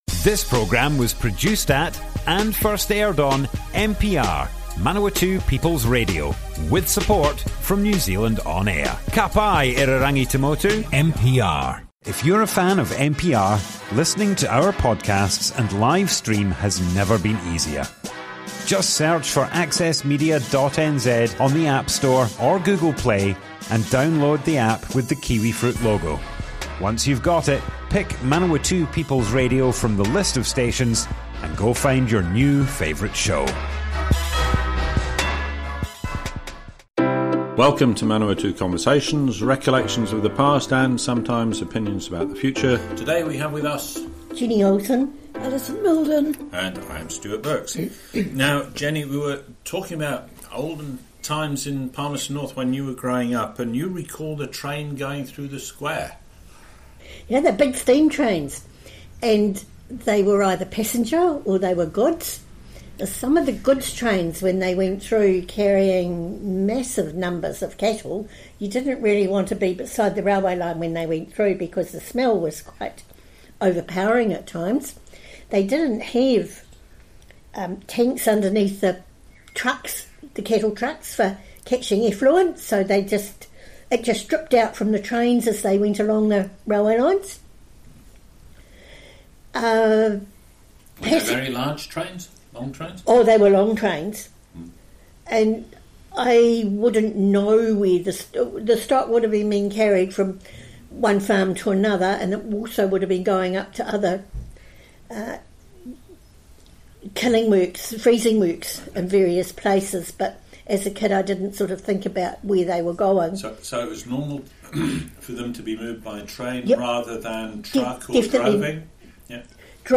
Manawatu Conversations More Info → Description Broadcast on Manawatu People's Radio, 4th February 2020.
oral history